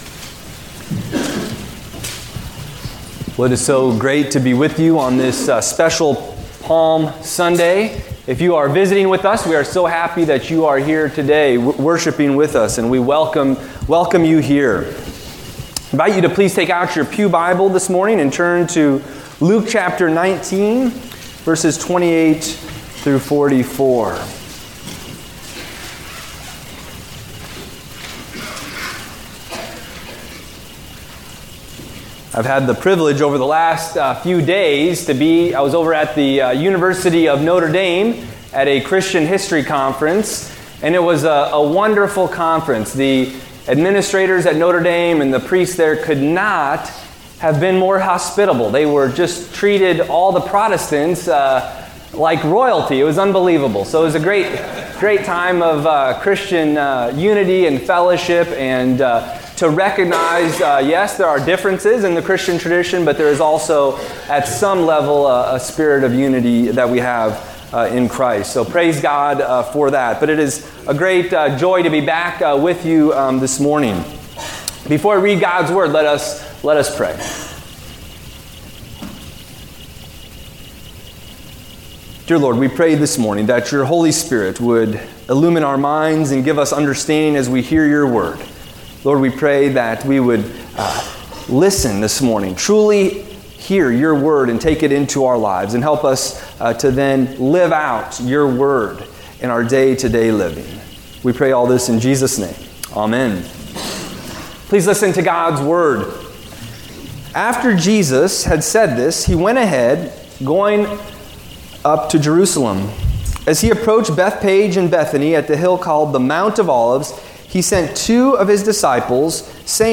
Service Type: Palm Sunday